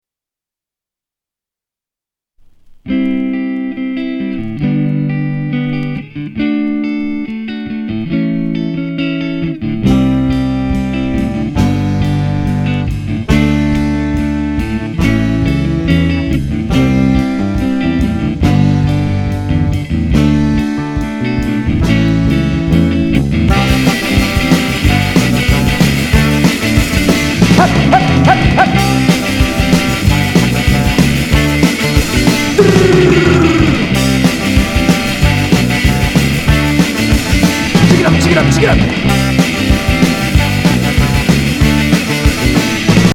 Oi